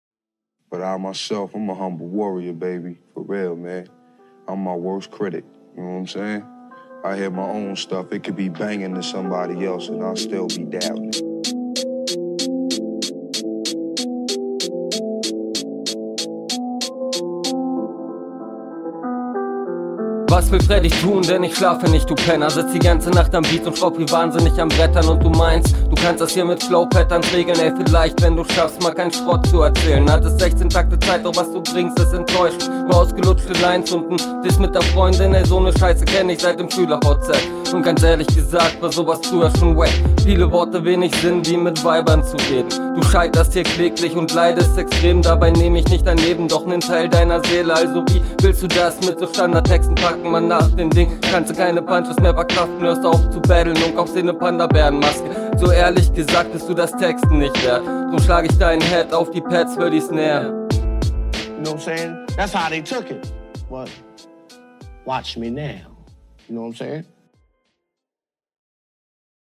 allerdings wirkt das ziemlich vernuschelt.